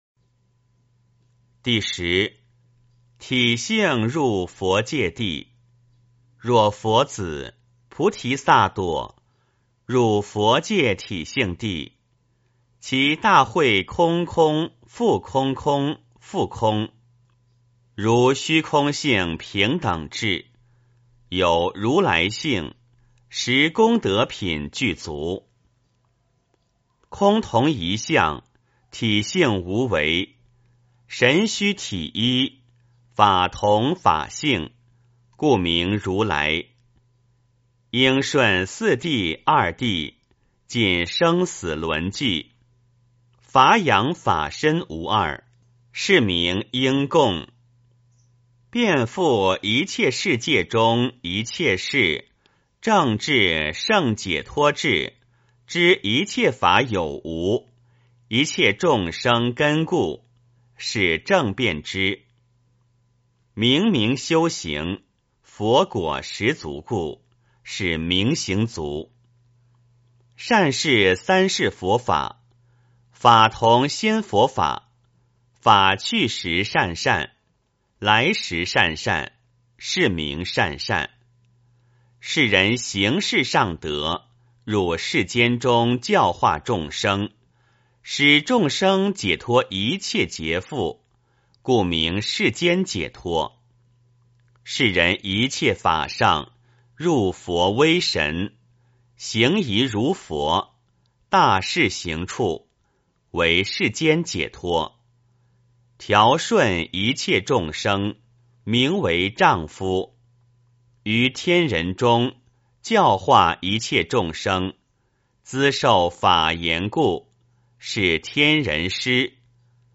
梵网经-十地-体性入佛界地 - 诵经 - 云佛论坛